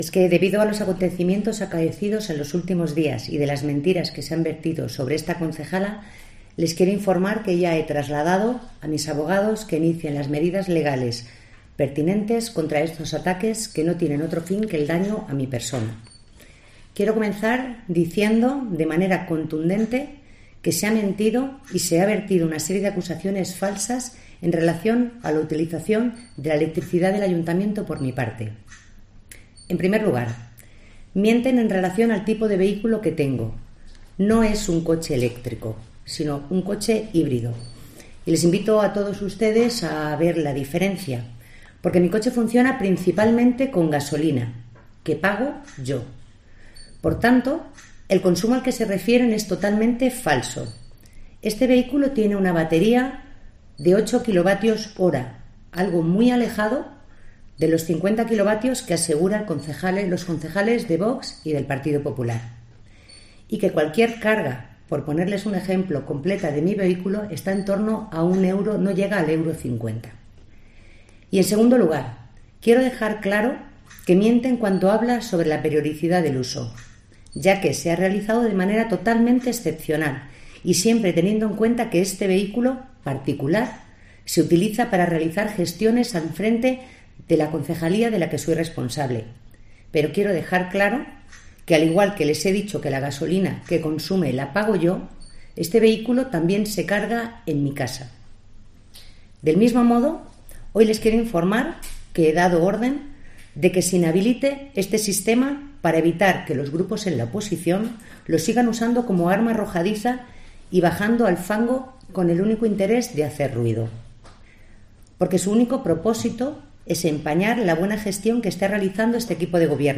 La senadora socialista, Montserrat Muro, ha comparecido hoy en rueda de prensa para explicar los motivos por los que estuvo cargando la batería de su coche "híbrido, no eléctrico" en un enchufe del garaje del Ayuntamiento.
Éste es el comunicado íntegro que ha leído en la comparecencia y que puedes escuchar de su propia voz pinchando en la foto de portada: